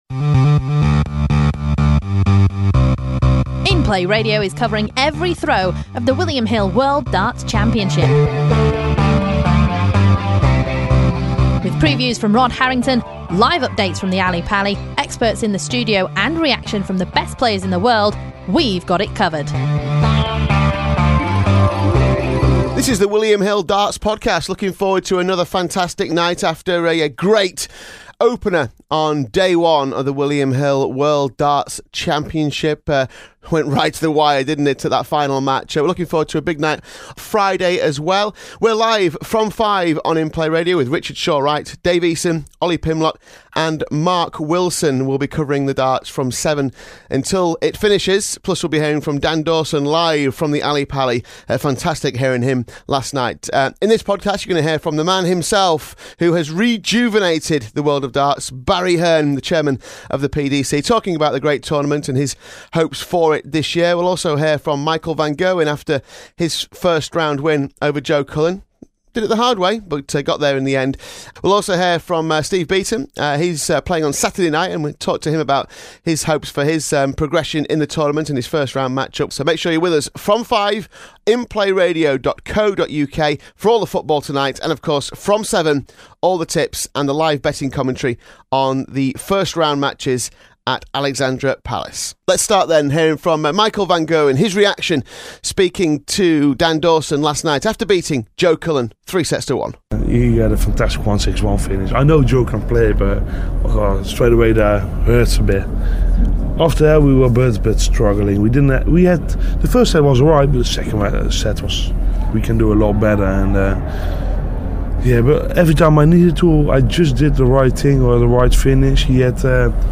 It's Day Two of the William Hill World Darts Championship and we've got an exclusive chat with the PDC chairman Barry Hearn.
He discusses the growth of the sport, this year's betting and plenty more. Plus you'll hear from reigning champion Michael van Gerwin after his first round win and Steve Beaton ahead of his opener.